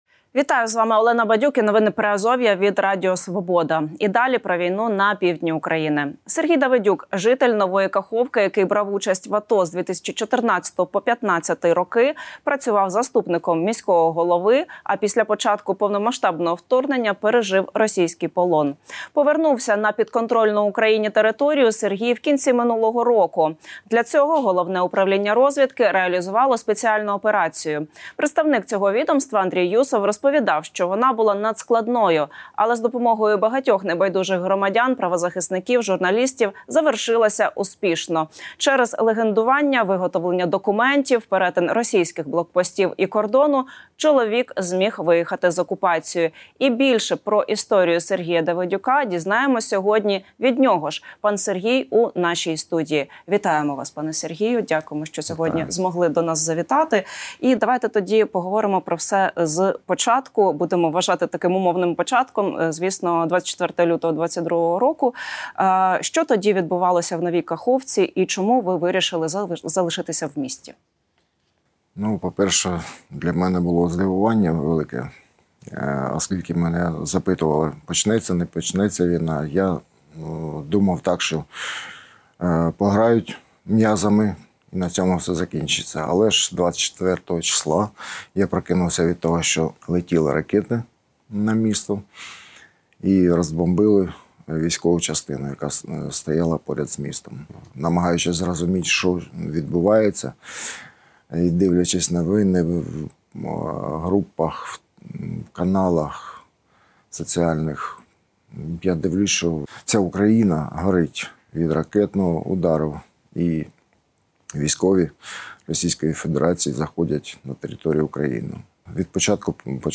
Інтерв'ю з полоненим з Херсонщини | Новини Приазовʼя